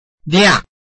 拼音查詢：【饒平腔】liag ~請點選不同聲調拼音聽聽看!(例字漢字部分屬參考性質)